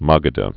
(mägə-də)